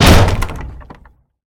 bang.ogg